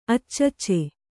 ♪ accacce